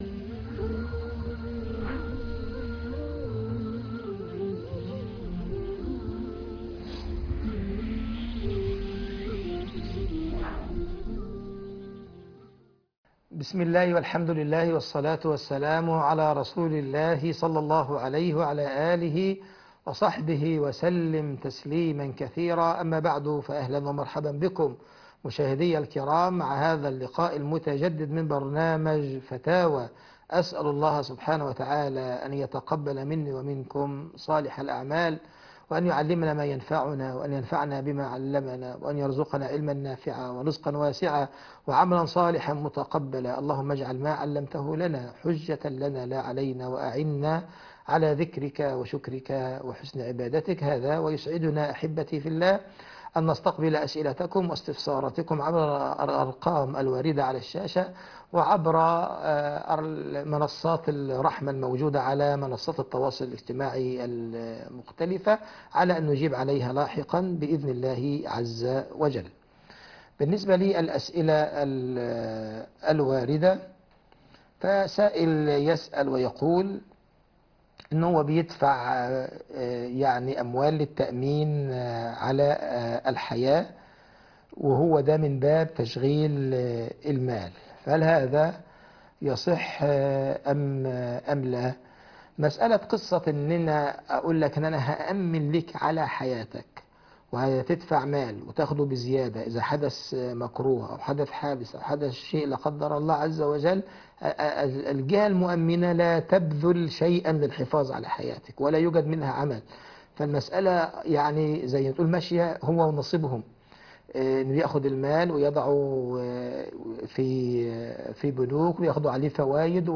فتاوي